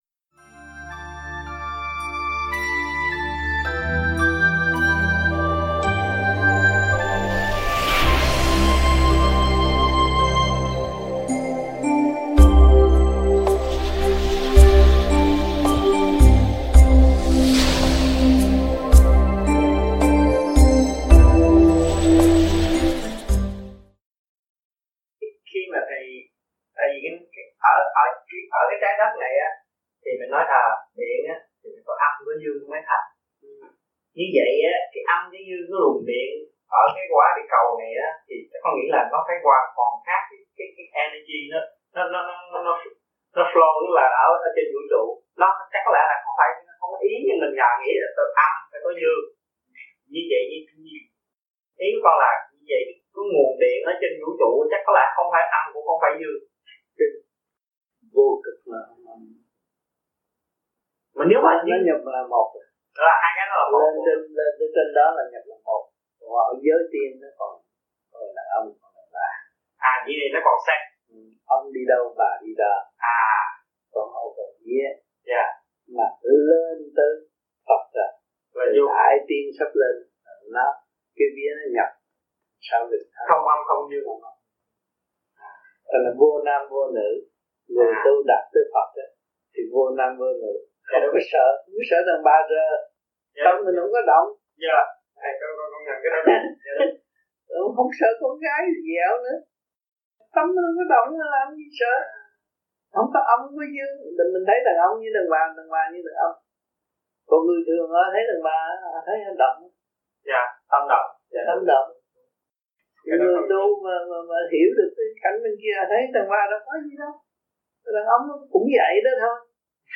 THUYẾT GIẢNG